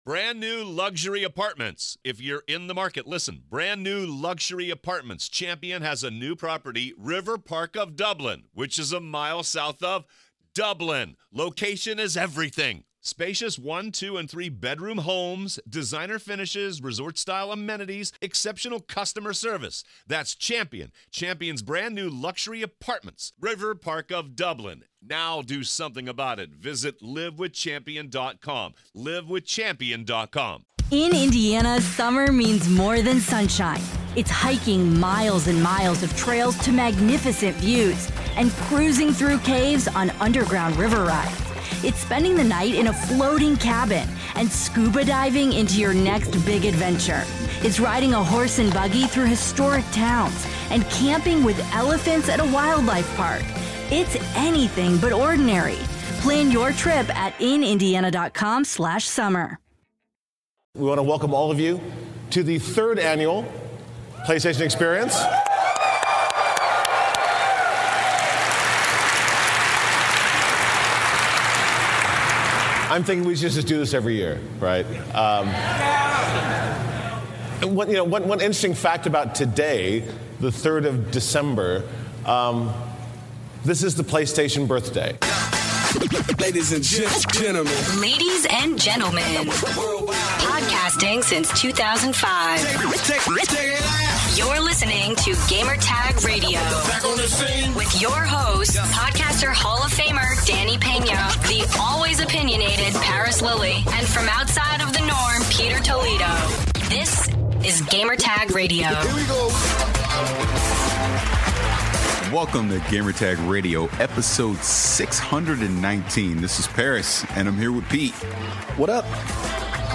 roundtable discussion about The Game Awards 2016 and Playstation Experience 2016 announcements.